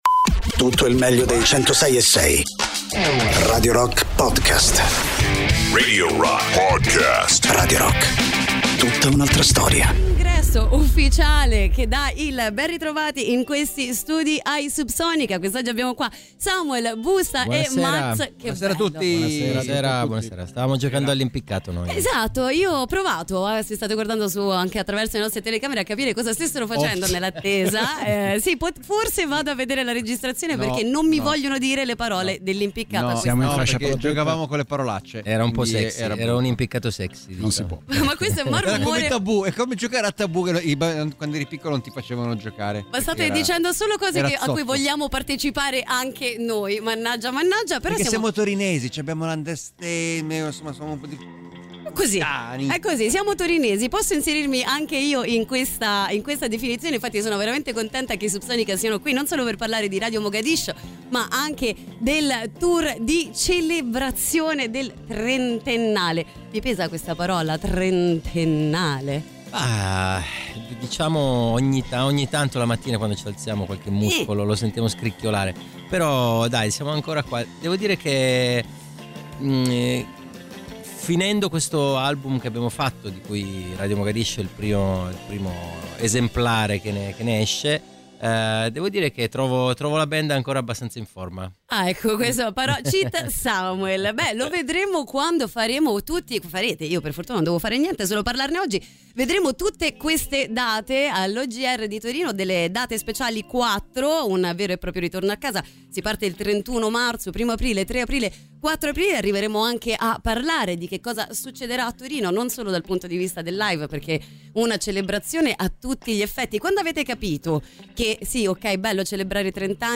Intervista: Subsonica (10-12-25)